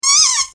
Squeak
Squeak.mp3